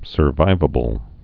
(sər-vīvə-bəl)